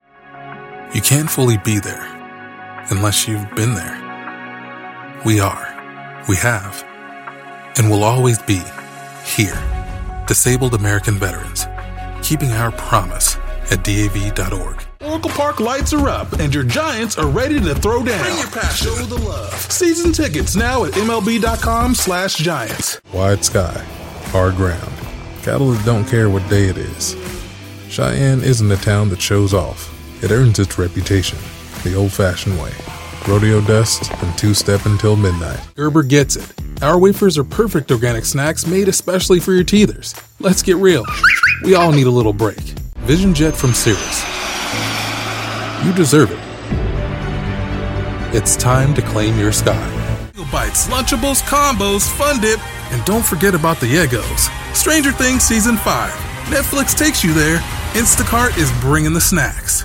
Authentic African American Male Voiceover Artist
Authentic African American male voice over artist for commercials, eLearning, and narration.